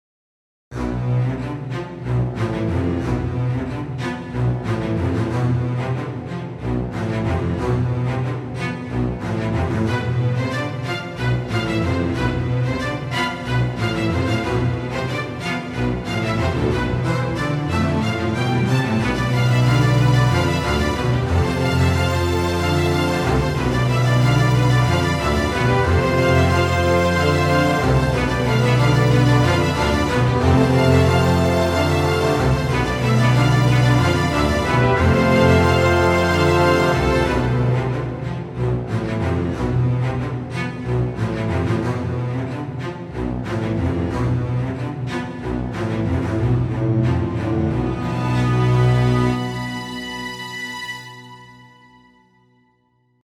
Locrian   2005 This doodle is a first stab at using GigaStudio. As an exercise, I wanted to write something in the Locrian mode. Since it is the seventh mode, I choose 7/8 for the time signature. There is more to this but will have to remain a doodle for now.(This mix has too much reverb; so it sounds best with headphones.)